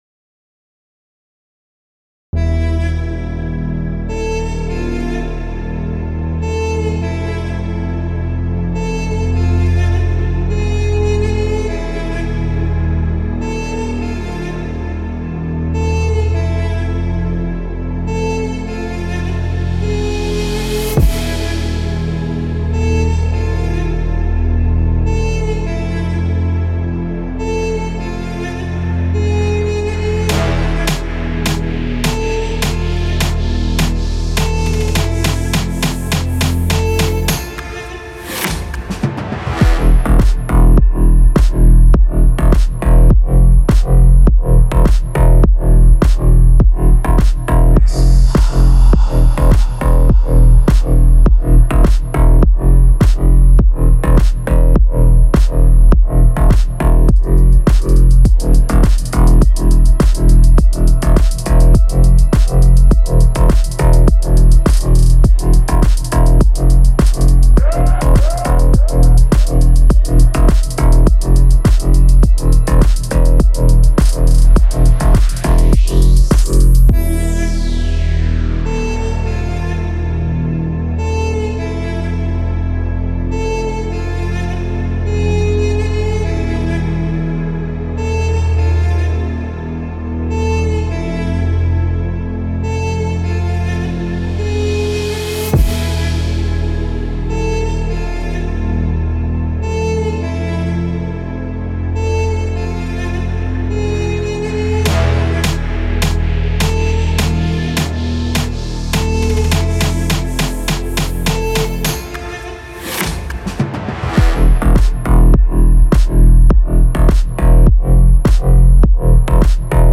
это песня в жанре инди-поп